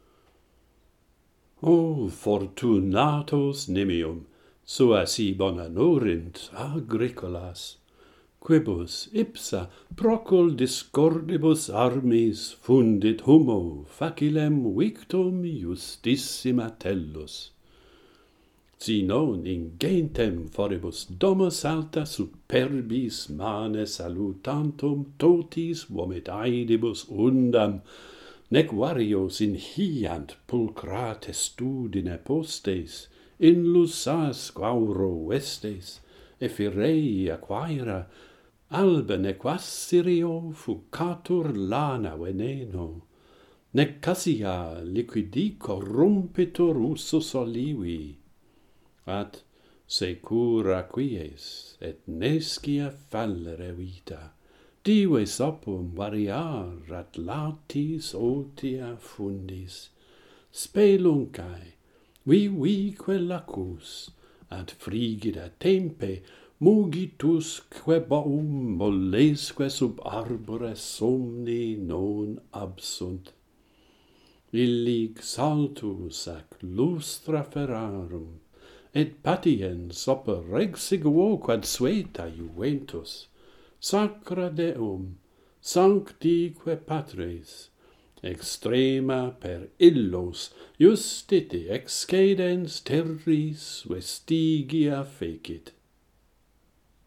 The farmer's happy lot - Pantheon Poets | Latin Poetry Recited and Translated